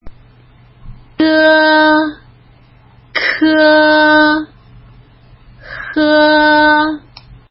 舌根音
g(e) (無気音)舌の根元を上あごにつけ、弱い息で｢ゴー｣と発音するイメージ。
k(e) (有気音)舌の根元を上あごにつけ、強い息で｢コー｣と発音するイメージ。
h(e) 舌の根元を上あごに近づけ、息で喉を擦るように｢ホー｣と発音するイメージ。